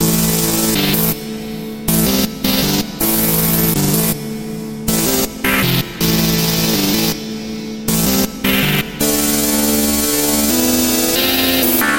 Tag: 160 bpm Electro Loops Synth Loops 1.01 MB wav Key : A